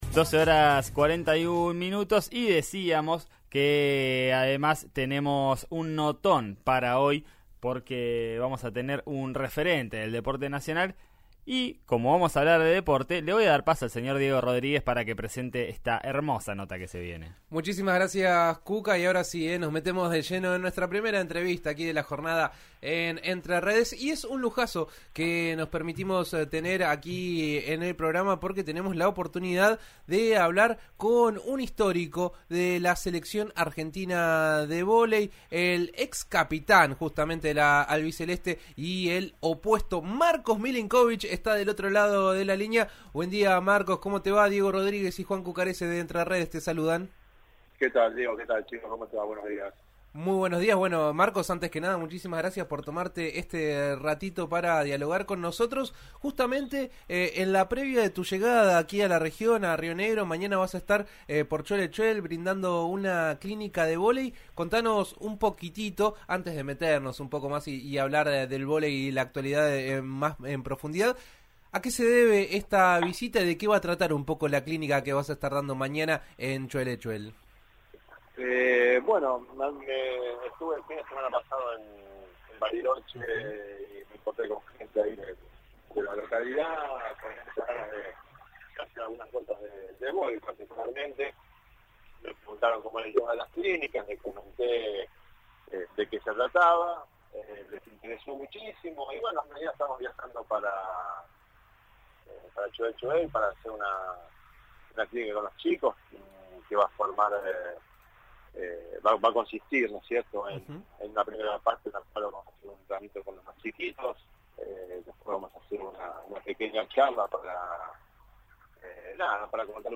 Antes de llegar a la región, el histórico capitán de la selección dialogó con Río Negro Radio , donde dio un pequeño anticipo de lo que sucederá en Valle Medio, analizó el presente del vóley nacional y destacó el gran nivel que atraviesa la Selección.